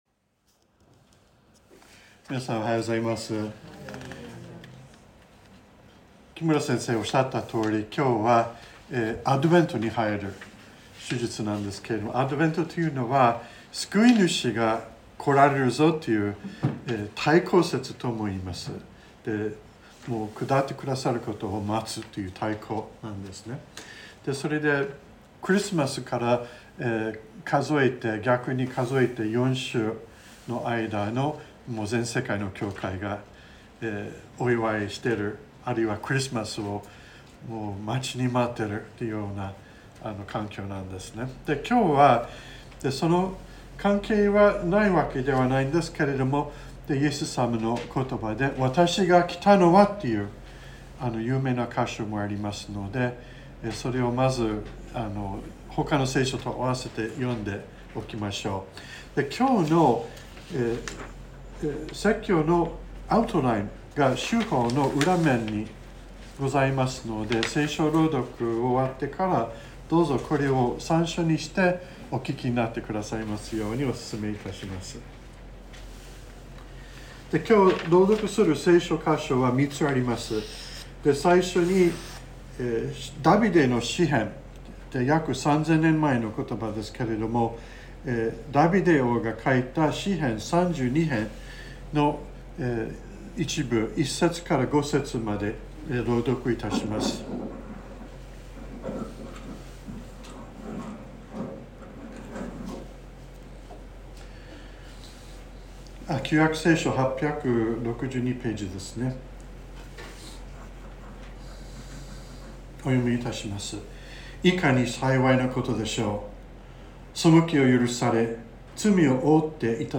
2021年11月28日朝の礼拝「負い目を支払う主」川越教会
説教アーカイブ。